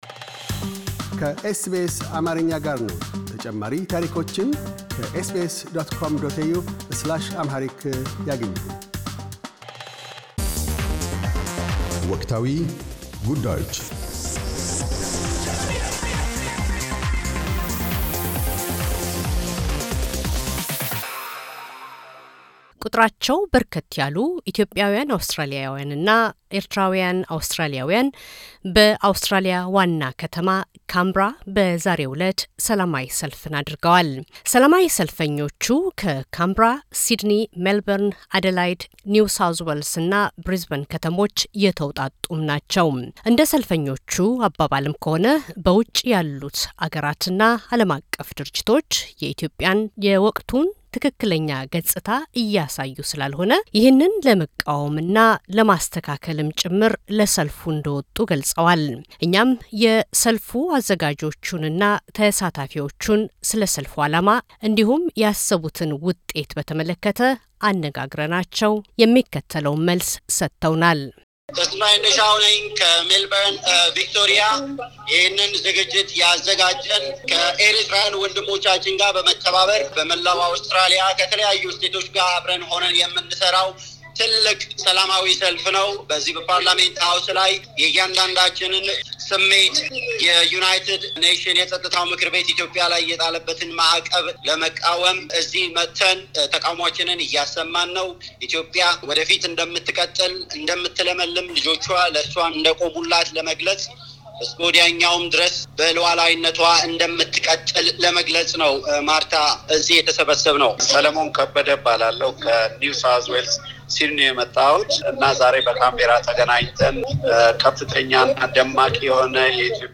ከአውስትራልያ የተለያዩ ከተሞች የተውጣጡ አትዮጵያውያን እና ኤርትራውያን አውስትራሊያውያን በአውስትራሊያ ዋና ከተማ ካምብራ በዛሬው እለት ሰላማዊ ሰልፍ ወጥተዋል ፡፡ የሰልፉን አላማ እና የሚጠብቁትን ውጤት በተመለከተ የሰልፉ አዘጋጆችን እና ተሳታፊዎች አነጋግረናል ፡፡